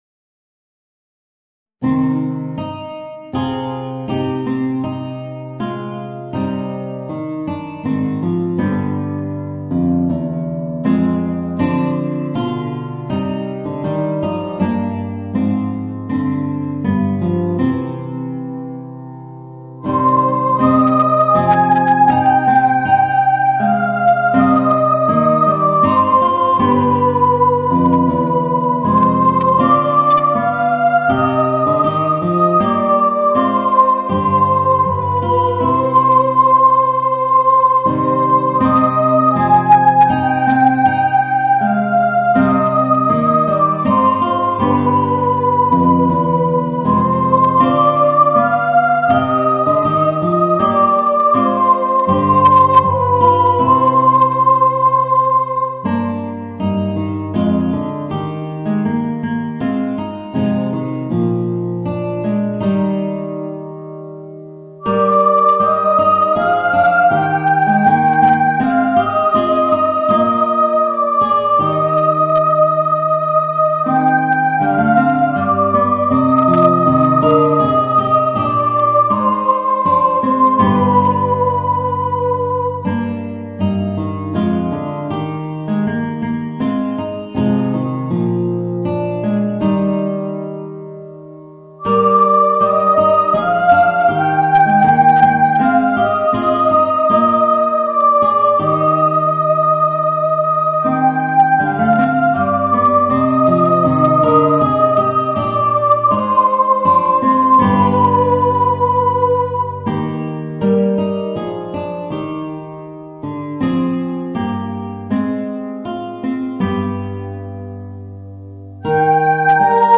Voicing: Soprano and Guitar